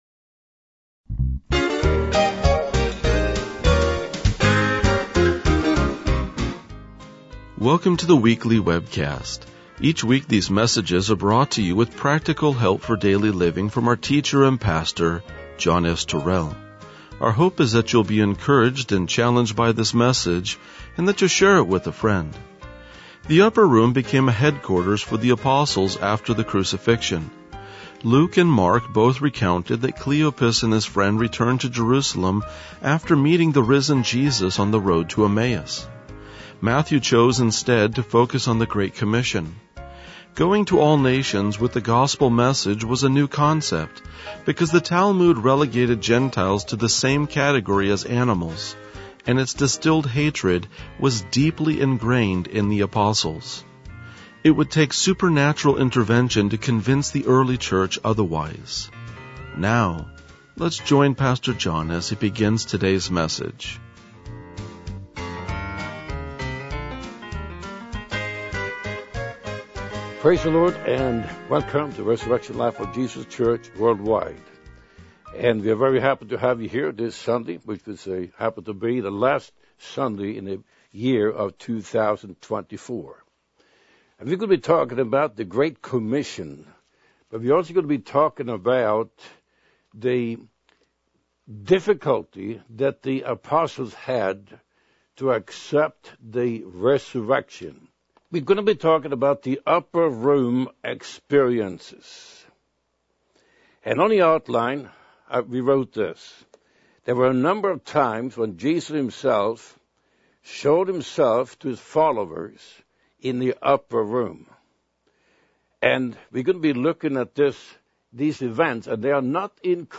RLJ-1995-Sermon.mp3